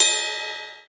Ride_04.wav